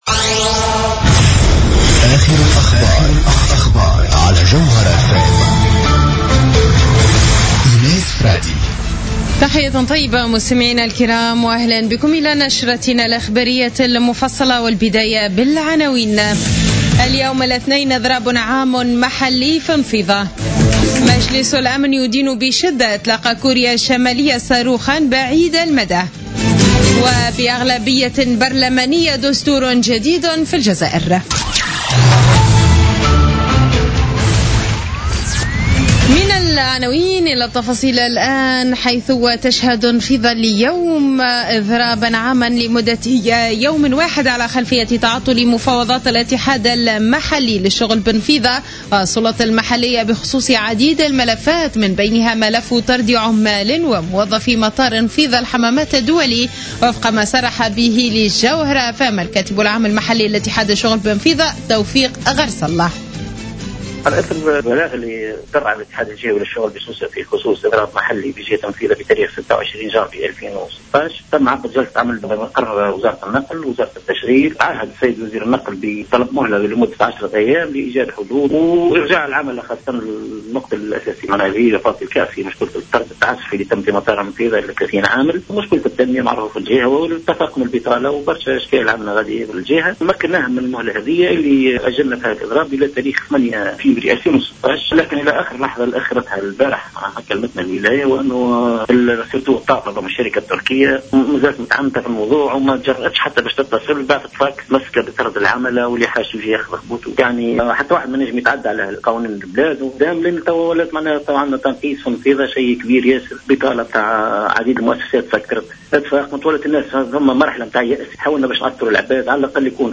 نشرة أخبار منتصف الليل ليوم الاثنين 8 فيفري 2016